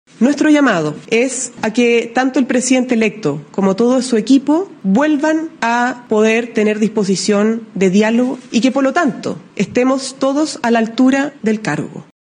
Luego, desde La Moneda, la portavoz de Palacio, Camila Vallejo, reforzó el mensaje y llamó a retomar el diálogo.